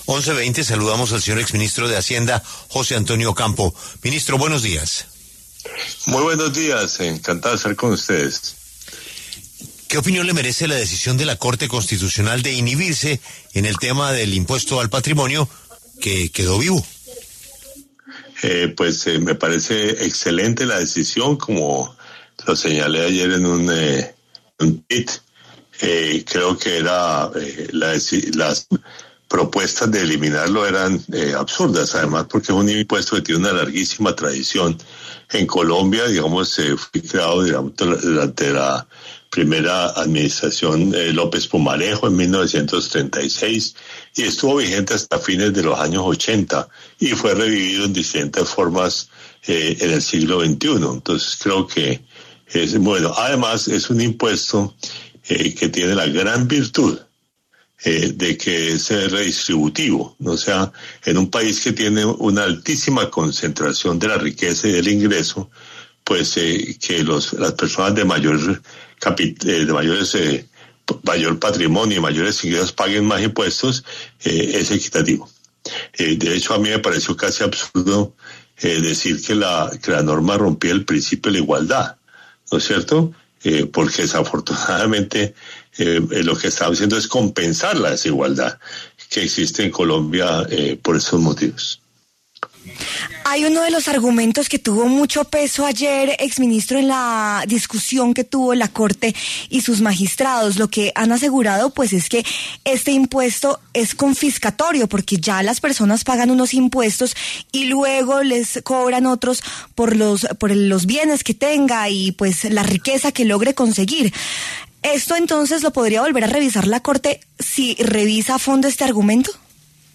A propósito de esto, La W conversó con el exministro José Antonio Ocampo para conocer cuáles son las consecuencias de esta decisión.